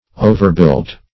Search Result for " overbuilt" : The Collaborative International Dictionary of English v.0.48: Overbuilt \O`ver*built"\, a. Having too many buildings; as, an overbuilt part of a town.